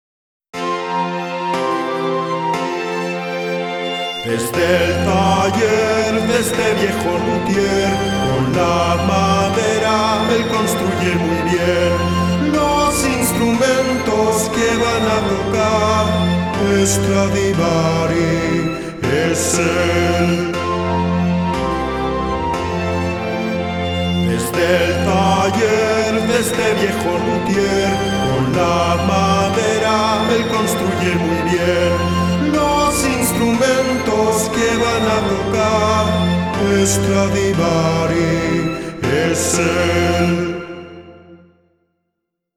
4. Para acabar vamos a cantar un poco con la canción "Stradivari" (pág.29, audición 31) este es el nombre de un famosísimo lutier del Barroco.